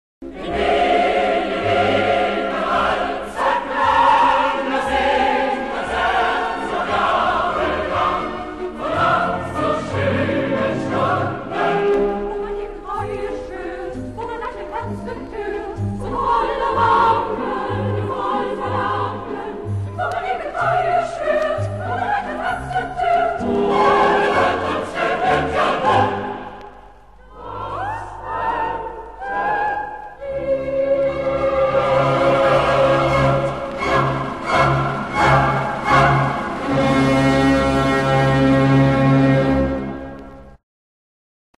Konzertwalzer
für gemischten Chor und Orchester
Besetzung: gemischter Chor, Klavier (ggf. 4-händig)